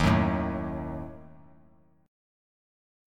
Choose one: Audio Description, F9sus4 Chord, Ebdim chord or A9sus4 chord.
Ebdim chord